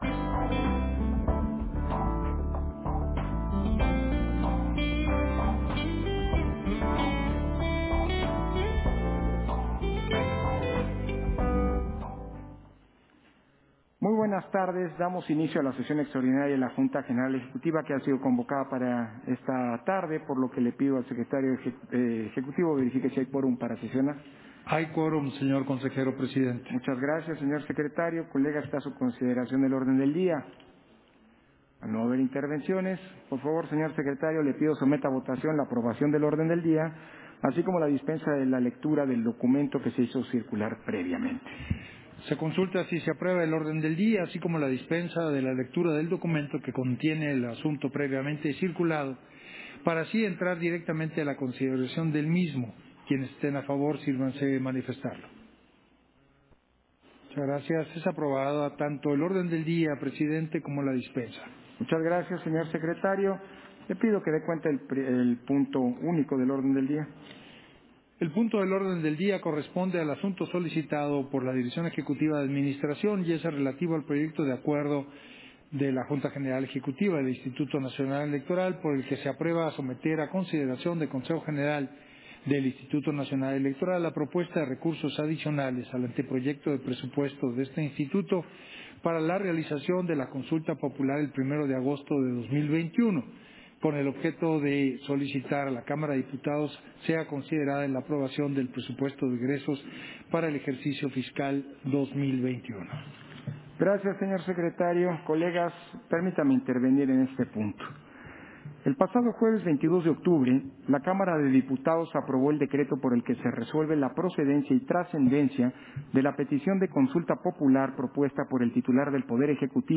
261020_-AUDIO_SESIÓN-EXTRAORDINARIA-DE-LA-JUNTA-GENERAL-EJECUTIVA